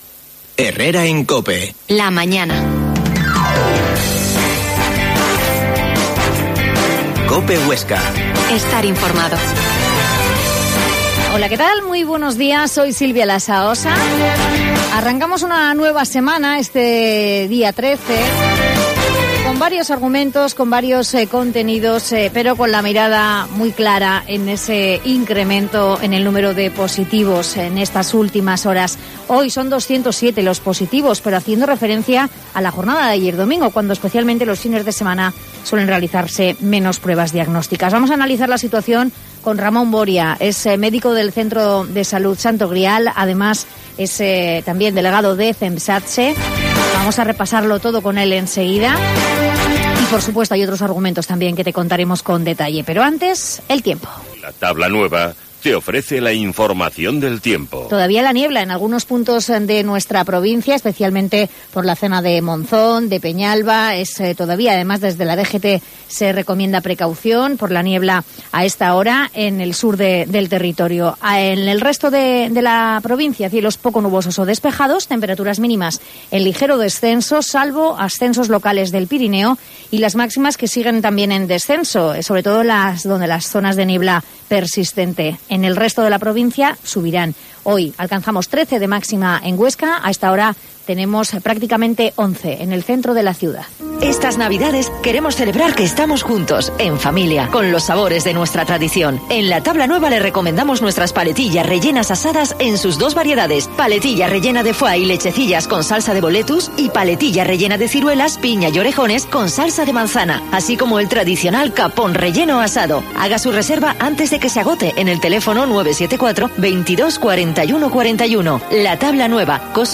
La Mañana en COPE Huesca - Informativo local Herrera en Cope Huesca 12,50h.